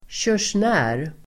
Ladda ner uttalet
körsnär substantiv, furrier Uttal: [tjör_sn'ä:r] Böjningar: körsnären, körsnärer Definition: person som tillverkar och säljer pälsvaror furrier substantiv, körsnär , pälsvaruhandlare [person som tillverkar och säljer pälsvaror]